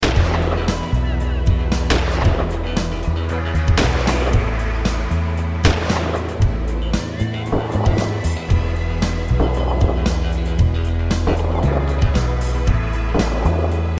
Hear the difference, listen to a hunting & shooting simulation below:
Hunting/Shooting Noise With and Without Plugfones
hunting.mp3